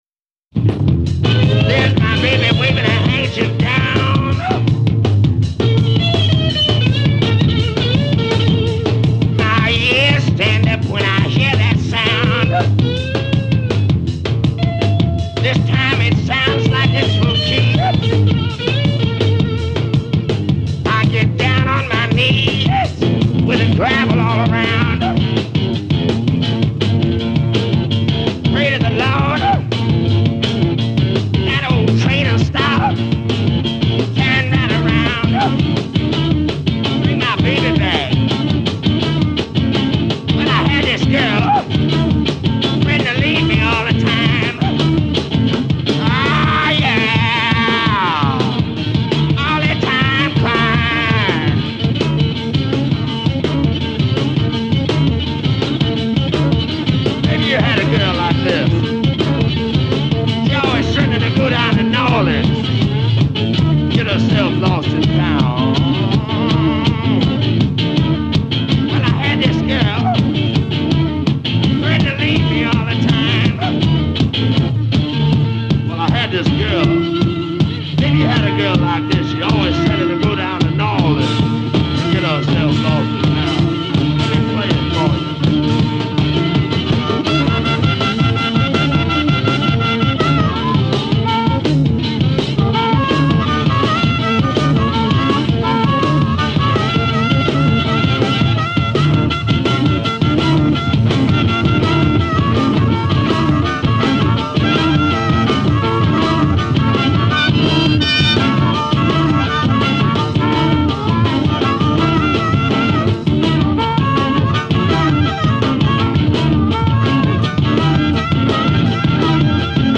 (1973 live)